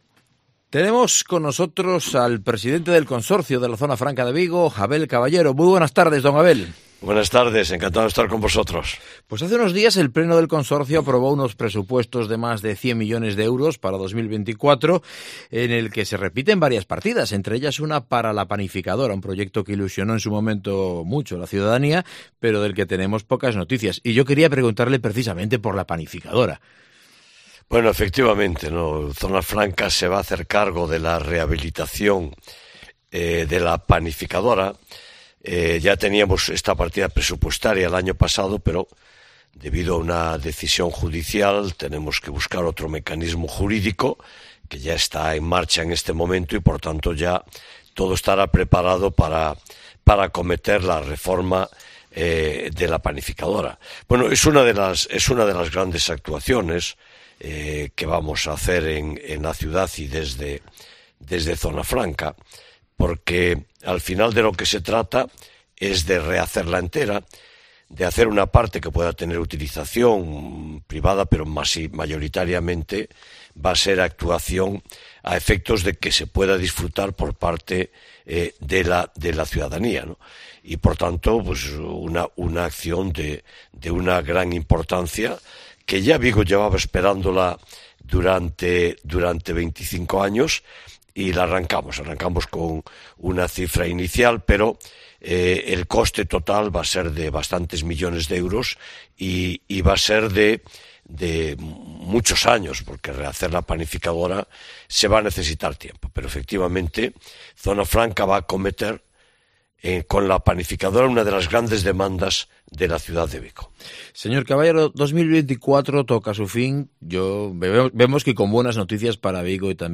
Entrevista con Abel Caballero, presidente de Zona Franca de Vigo